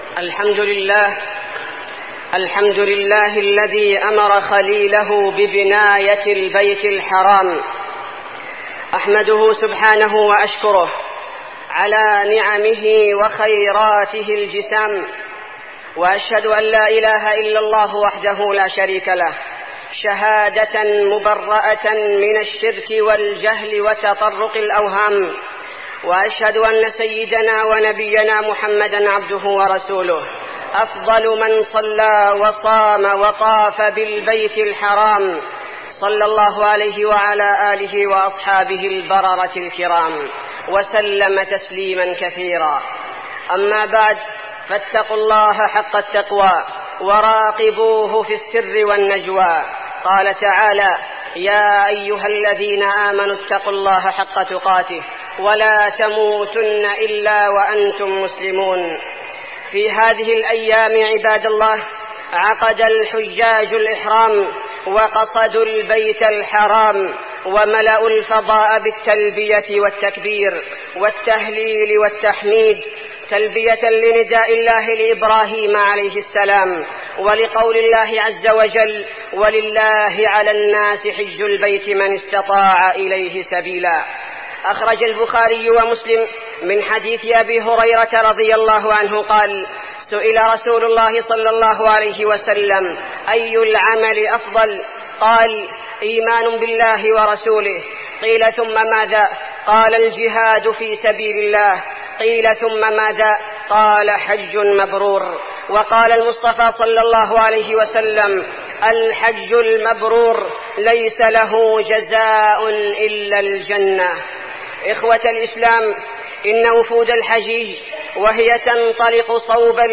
تاريخ النشر ٢ ذو الحجة ١٤١٦ هـ المكان: المسجد النبوي الشيخ: فضيلة الشيخ عبدالباري الثبيتي فضيلة الشيخ عبدالباري الثبيتي فضل الحج وعشر ذو الحجة